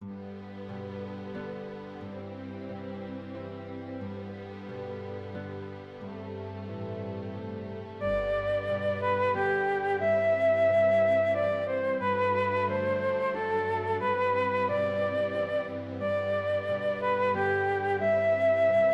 オーケストレーション：前半・フルートの旋律
弦楽器とハープの伴奏に、
フルートの旋律が出てきます。
旋律と伴奏の音域が、かぶらないように